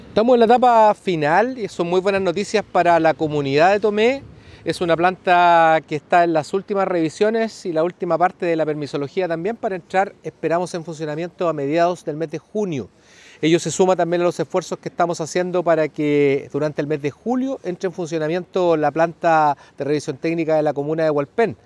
El seremi de Transportes del Bío Bío, Patricio Fierro, valoró la inversión que se realizó para contar con esta nueva infraestructura. Aseguró que la planta está en su etapa final de obtener los permisos y que además se espera que la de Hualpén comience a operar en julio de este año.